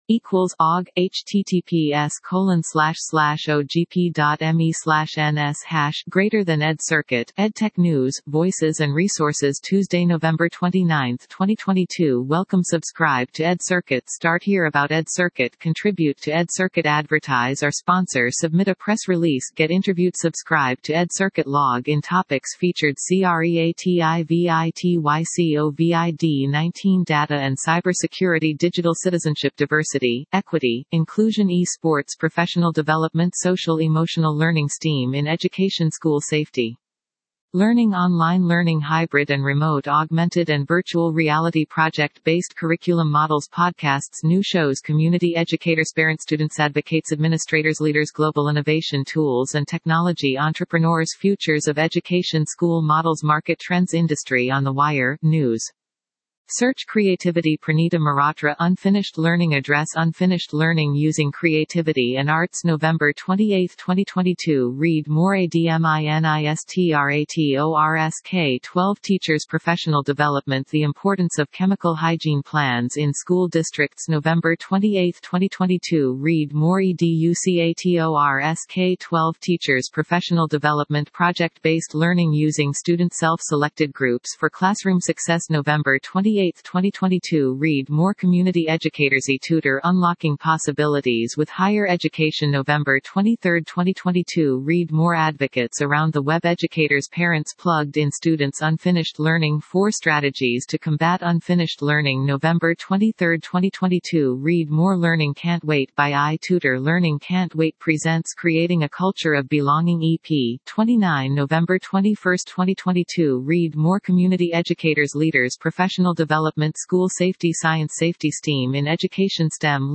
As you listen through the interview, think through these questions to consider your own leadership journey: